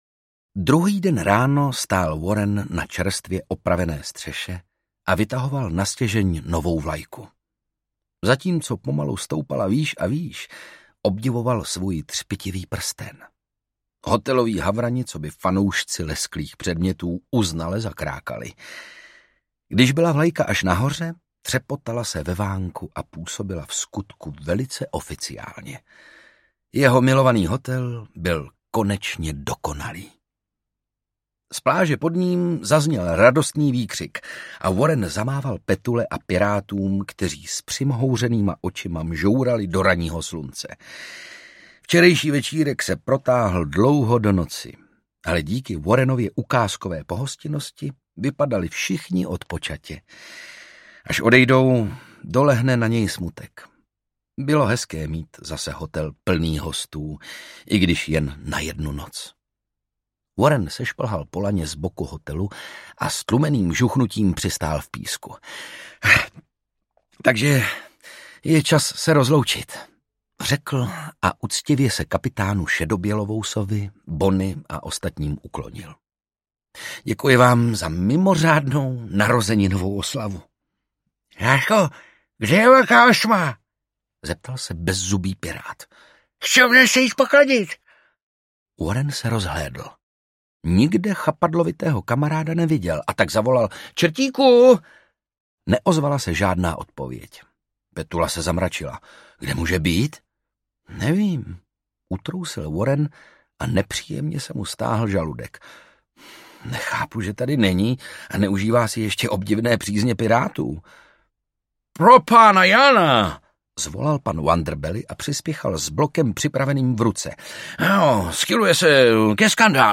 Warren XIII. a prokleté třináctiny audiokniha
Ukázka z knihy
Čtou Ondřej Brousek, Otakar Brousek.
Vyrobilo studio Soundguru.
• InterpretOndřej Brousek, Otakar Brousek ml.